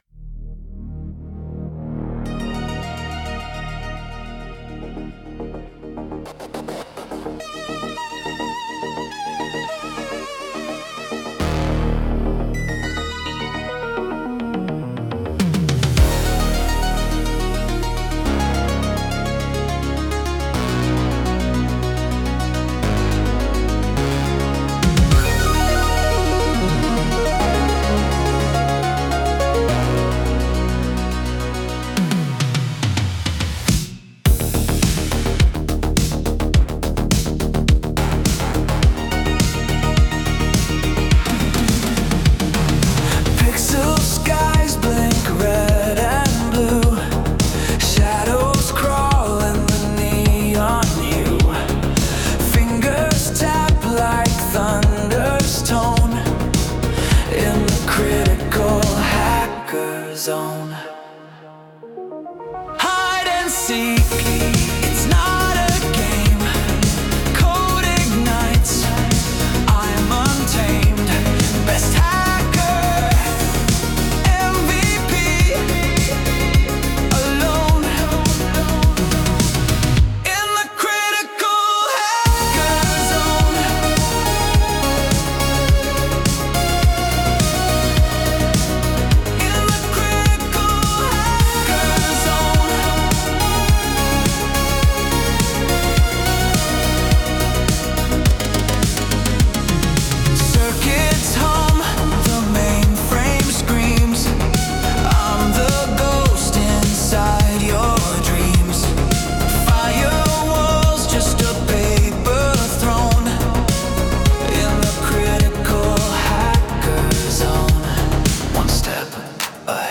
synthwave soundtrack
Genre: Synthwave / Cyberpunk / Retro Electronic